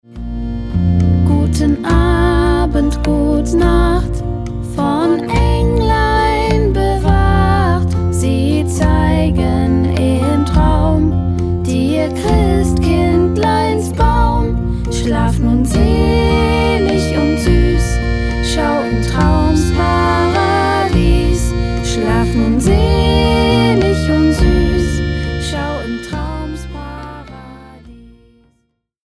Klassische Schlaflieder in neuem Sound